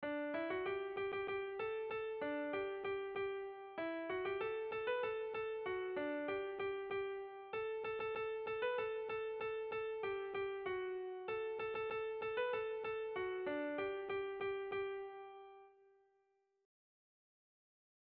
Dantzakoa
Kopla doinua.
AB